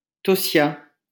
Tossiat (French pronunciation: [tɔsja]